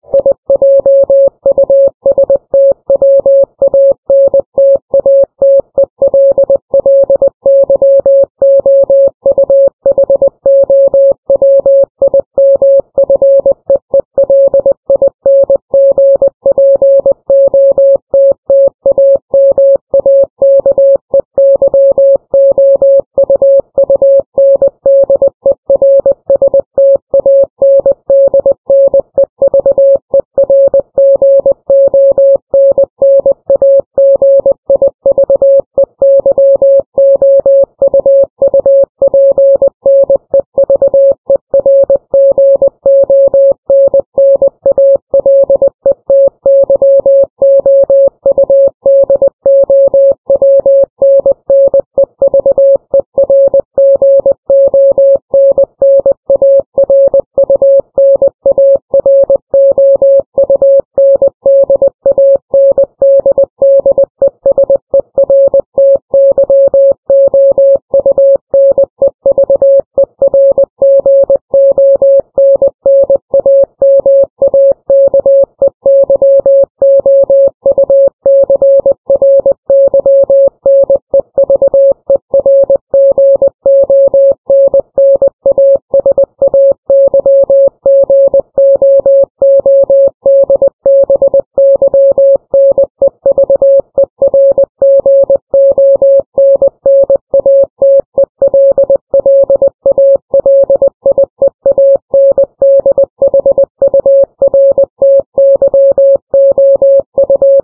too fast for me.
morse.mp3